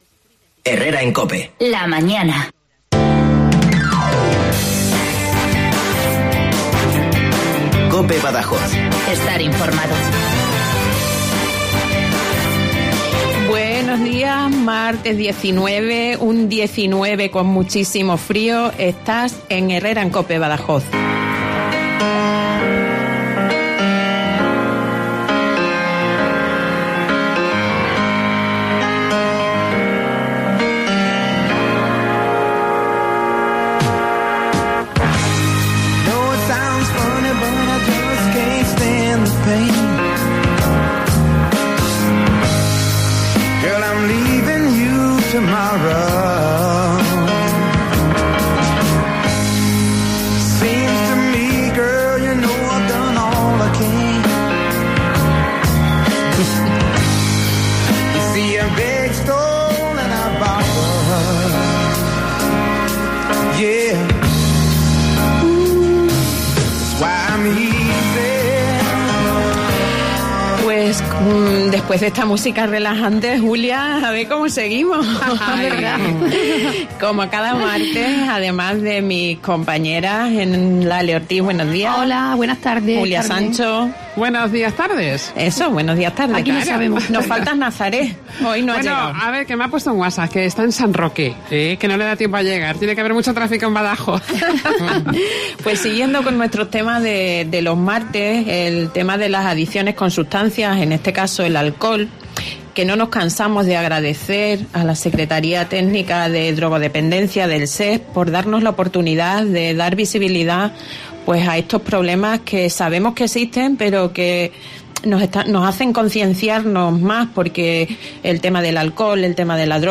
Hoy, en Herrera en COPE Badajoz hemos contado con dos testimonios muy valiosos de personas que tocaron fondo por una adicción pero que con decisión y ayuda, lograron salir.